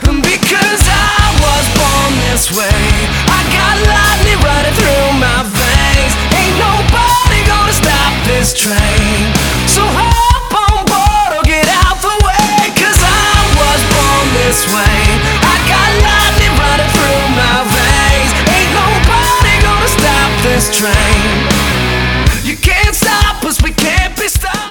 • Качество: 192, Stereo
мужской вокал
Hard rock
Мощный рок в песне